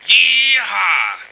M1yeehaw.ogg